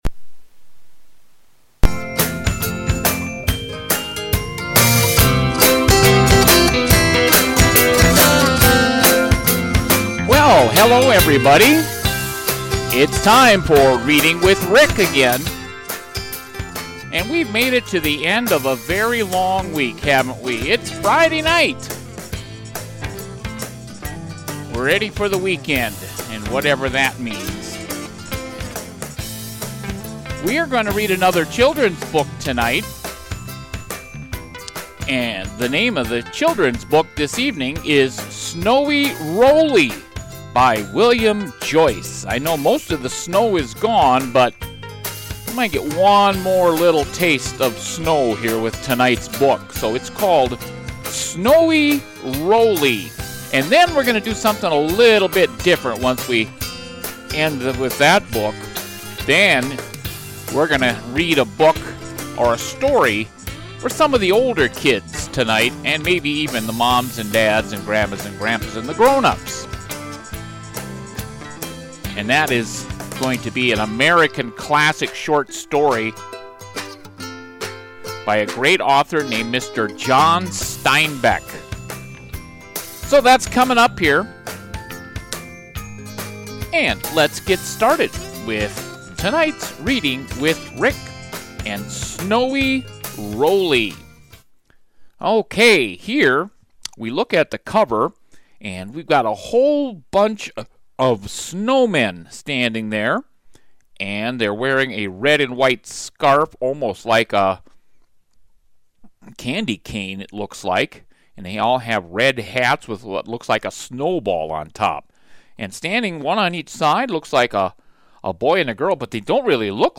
Then we settle in for the telling of an American Classic short story from John Steinbeck.